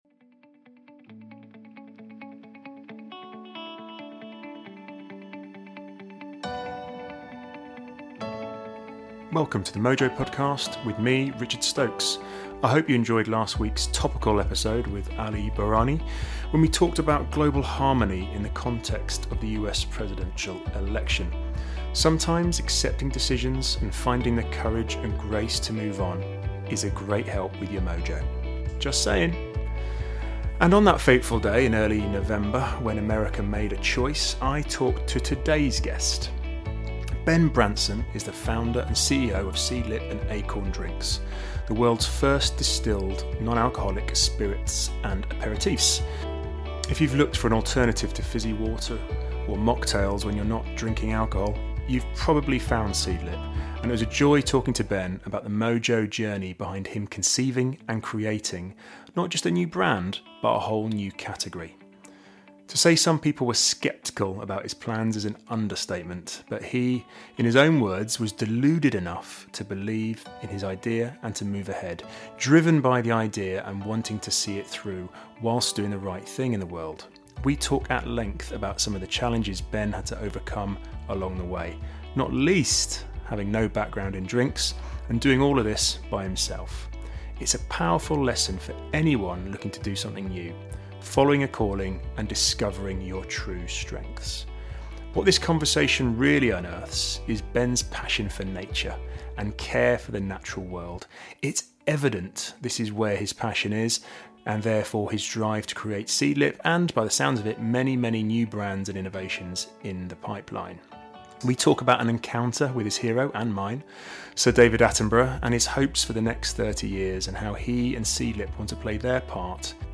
It's a revealing and inspiring conversation.